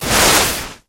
Звуки санок
Шум тормозящих санок по снегу